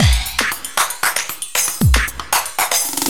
LOOP30--01-R.wav